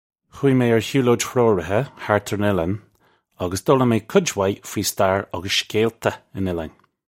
Khoo-ee may ar hyoo-loadge h-roar-eeha hart urr un ill-an uggus doh-lim may kudge wye fwee star uggus shkaylta un il-line. (U)
This is an approximate phonetic pronunciation of the phrase.
This comes straight from our Bitesize Irish online course of Bitesize lessons.